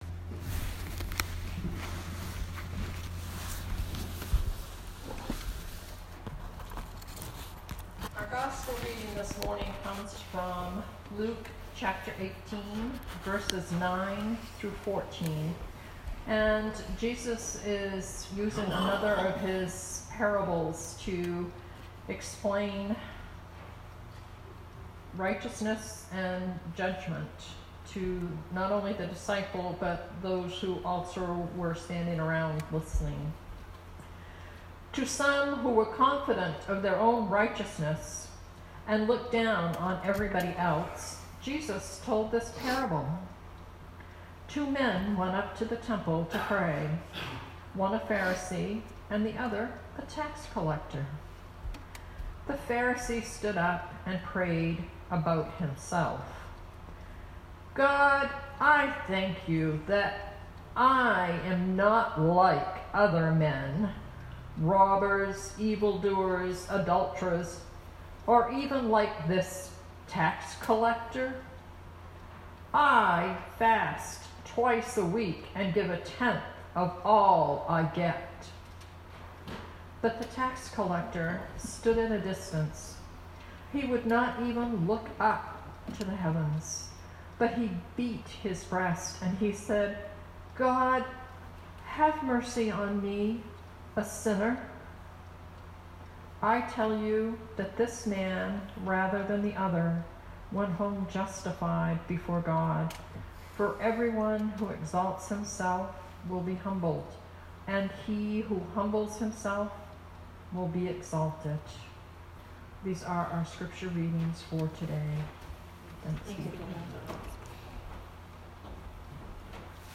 Sermon 2019-10-27